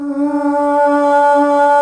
AAAAH   D.wav